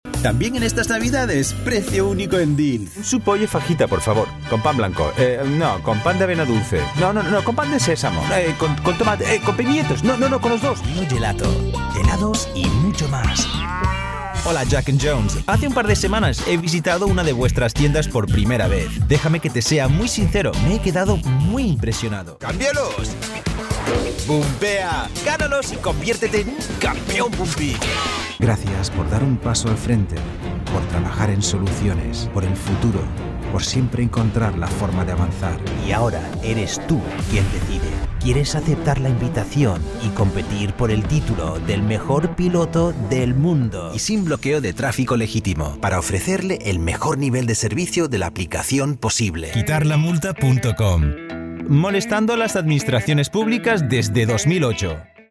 Male
Authoritative, Bright, Cheeky, Conversational, Corporate, Engaging, Friendly, Natural, Warm
Voice reels
Microphone: Neumann TLM 103 & Sennheiser MKH 416